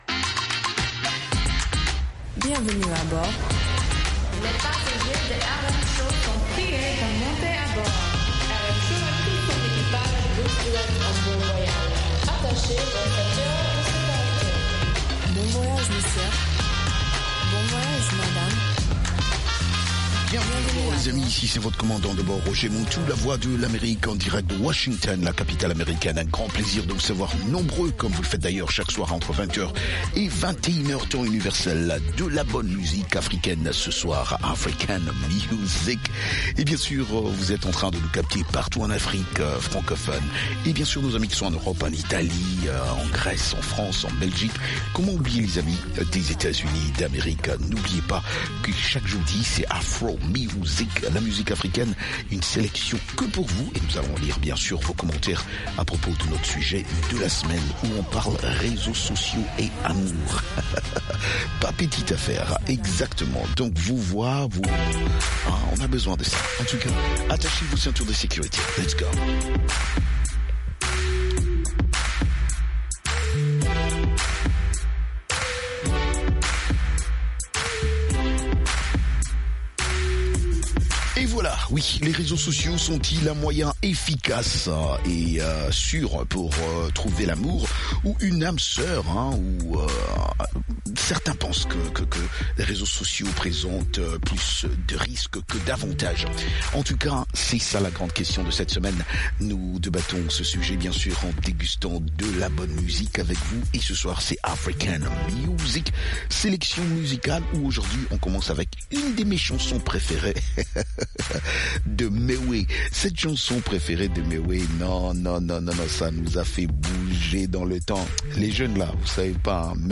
est une émission radio interactive mêlant entertainment, musique rap et débats ouverts, où la parole est donnée aux auditeurs et auditrices. L’émission aborde des thématiques socio-culturelles, économiques et d’actualité, avec un accent particulier sur les enjeux économiques de l’été, les initiatives locales, l’entrepreneuriat, la jeunesse et la créativité urbaine.